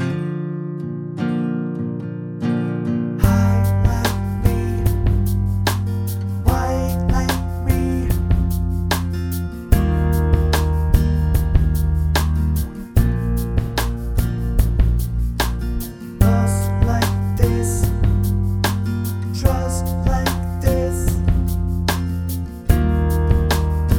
Original Version Pop (2010s) 4:40 Buy £1.50